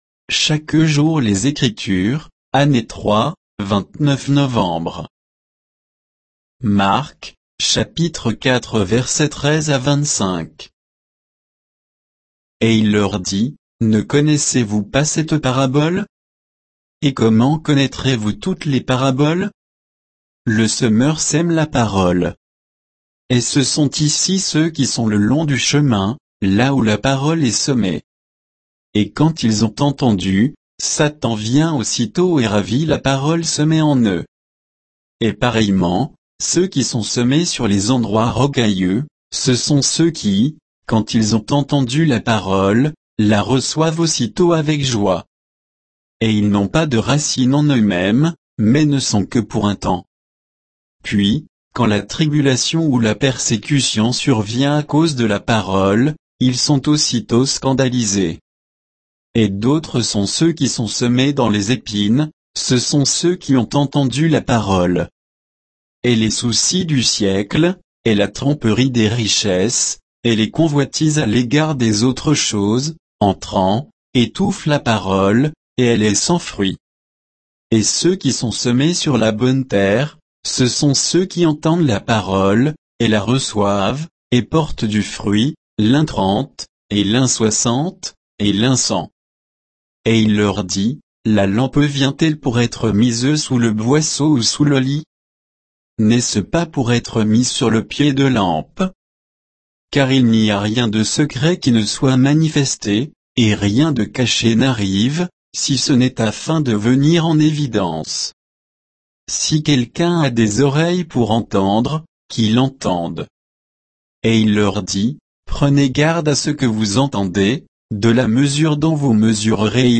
Méditation quoditienne de Chaque jour les Écritures sur Marc 4